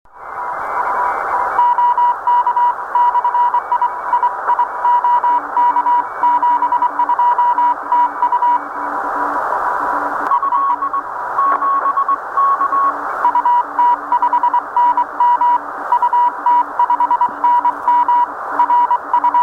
Это в поле, точнее в лесу, приём на Р-143.